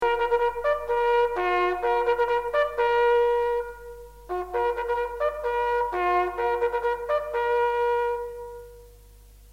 First Dinner Call
First-Dinner-Call.mp3